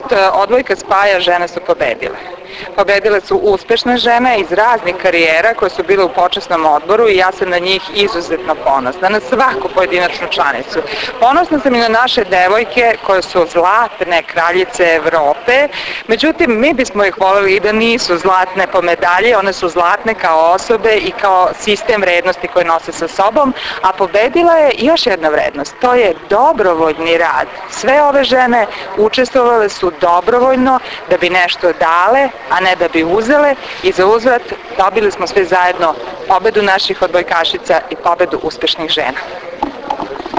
IZJAVA SNEŽANE SAMARDŽIĆ – MARKOVIĆ